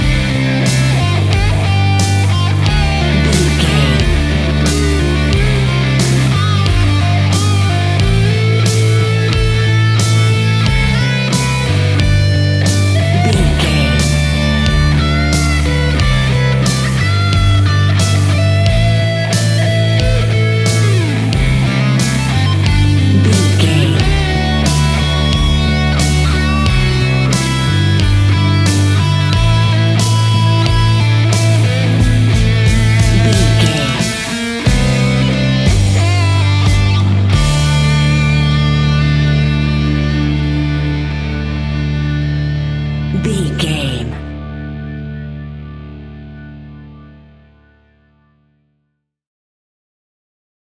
Mixolydian
hard rock
blues rock
instrumentals
Rock Bass
heavy drums
distorted guitars
hammond organ